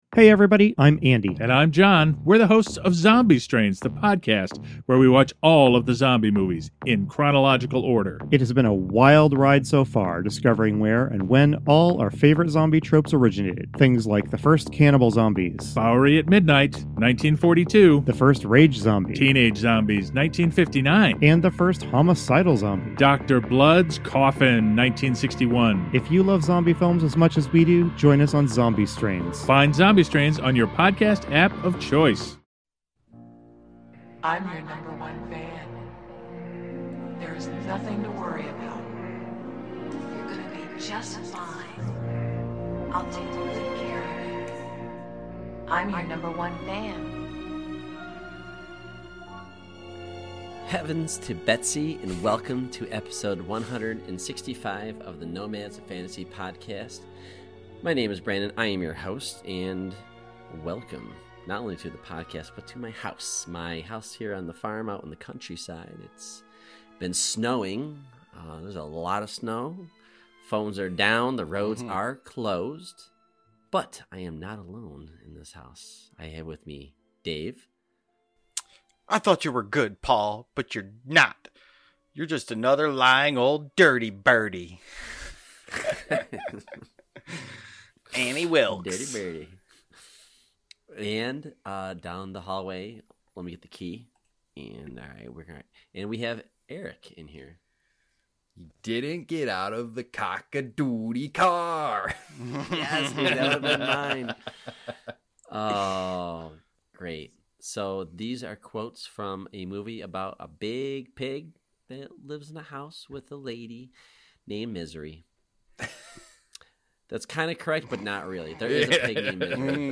Movie, TV show and video game discussions